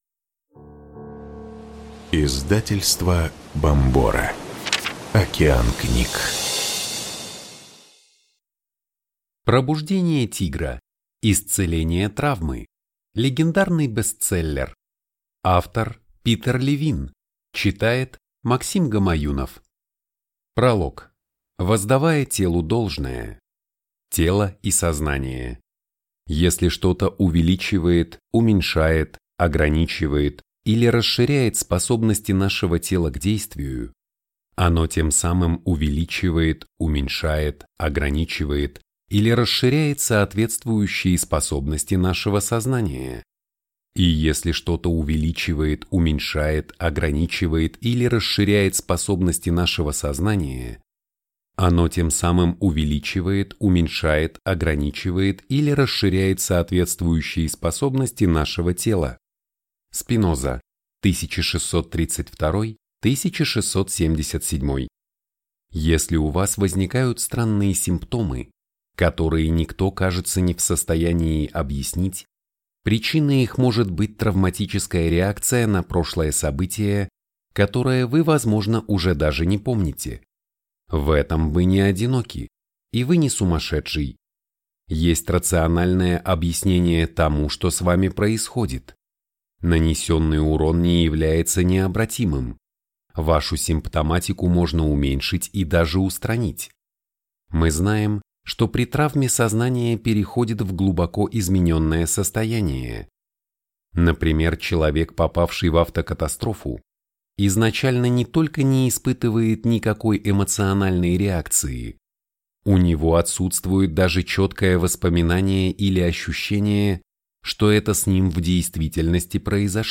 Аудиокнига Пробуждение тигра. Исцеление травмы. Легендарный бестселлер | Библиотека аудиокниг